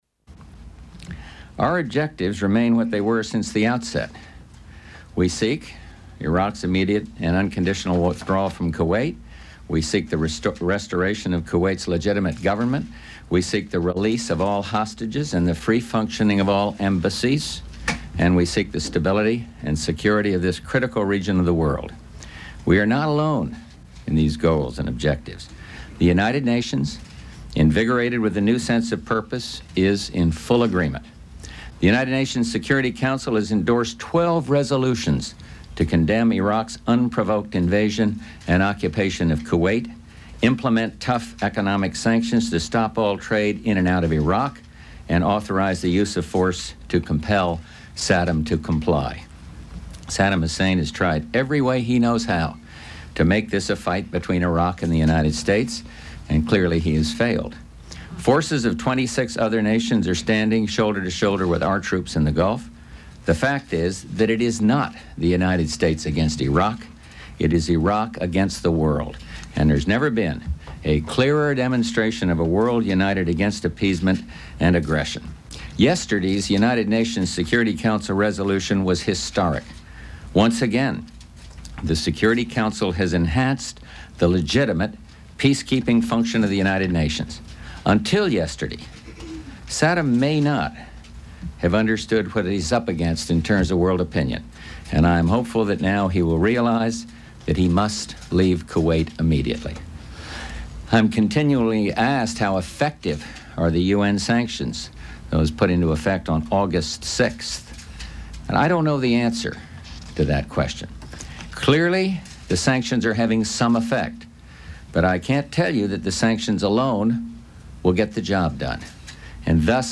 Press conference with a statement about the previous day's United Nations Security Council vote endorsing the use of military force against Iraq